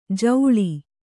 ♪ jauḷi